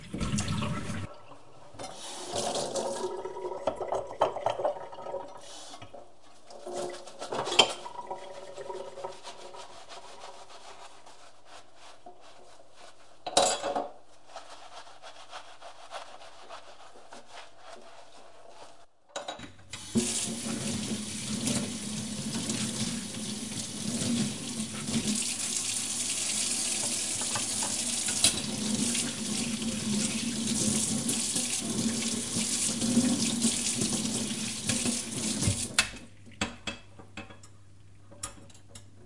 厨房洗碗碟刀具的声音
描述：厨房洗碗碟刀具的声音。
标签： 集合 厨房 碗碟 洗涤 刀具
声道立体声